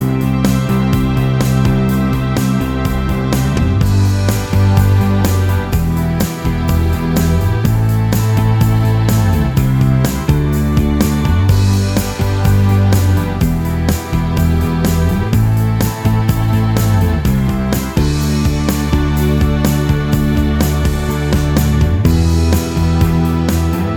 Minus Guitars Indie / Alternative 3:55 Buy £1.50